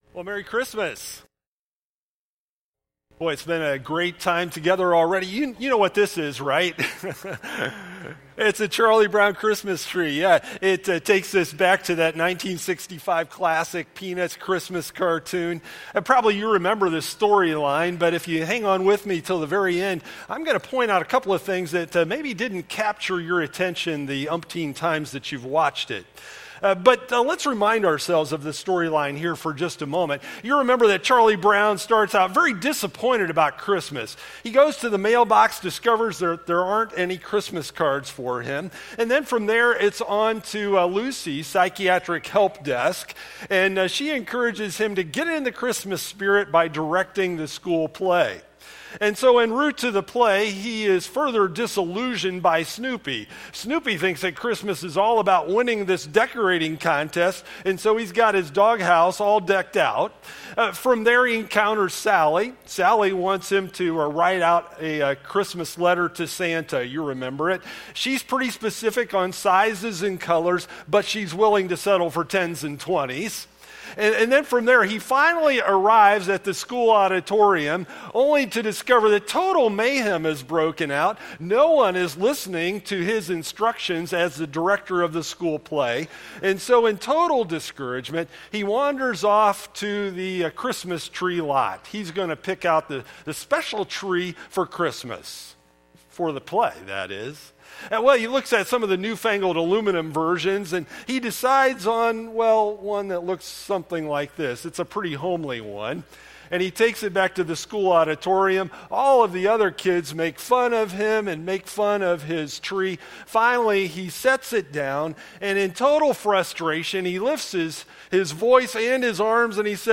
Listen to the TRADITIONAL sermon